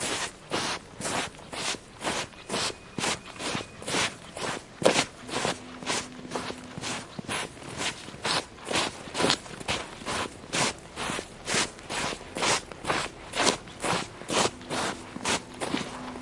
描述：晚上独自行走。用iphone 5录制
Tag: 晚上 步骤 走路 意大利帕尔马